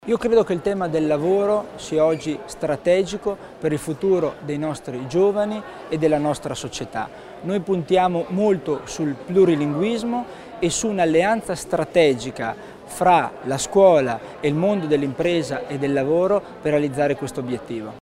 L'Assessore Christian Tommasini illustra le finalità dell'accordo tra mondo scolastico e mondo del lavoro